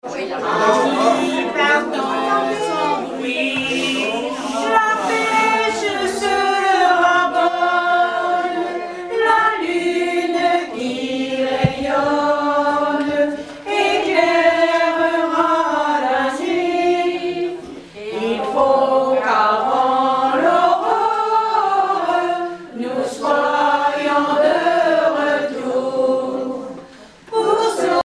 Genre strophique
Collecte de chansons
Pièce musicale inédite